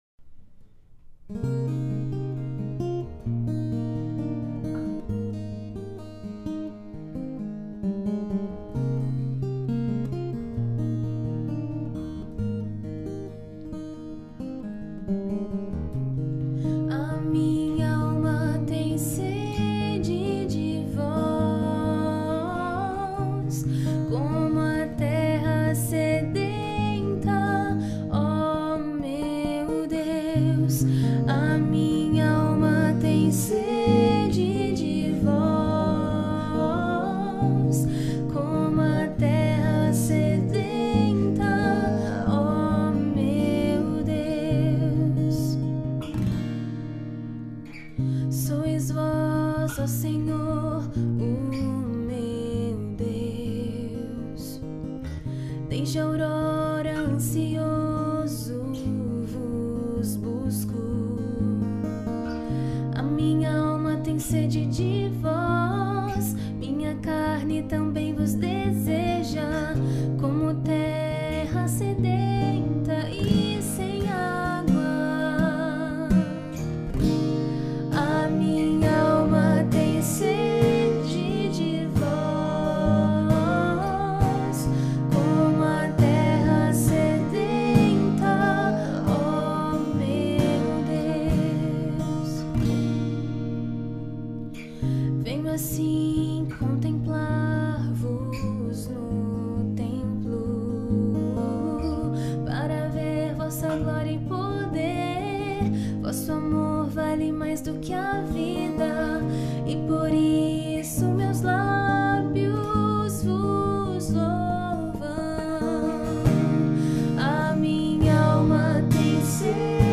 Salmo-62-22o-Domingo-Tempo-Comum-A-minha-alma-tem-sede-de-vos-como-terra-sedenta-o-meu-Deus.mp3